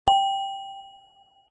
gb_ding.ogg